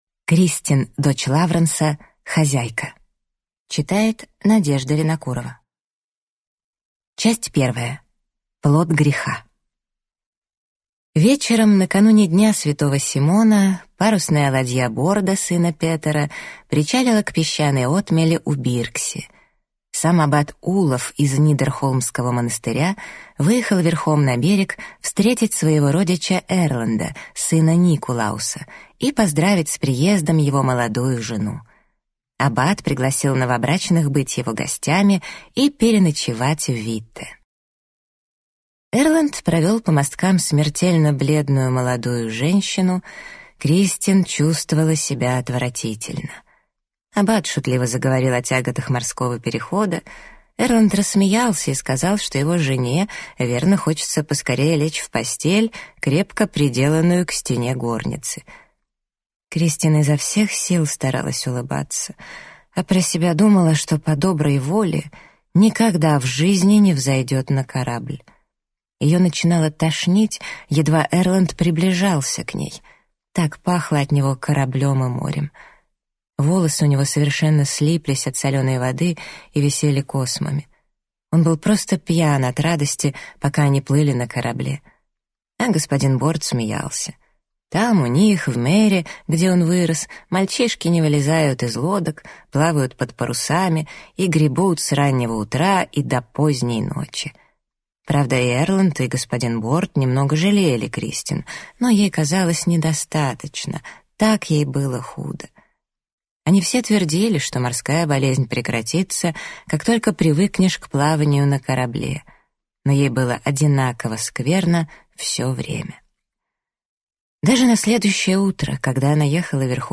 Аудиокнига Хозяйка | Библиотека аудиокниг